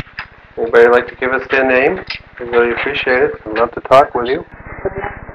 EVP's
This female voice is answering a request for names.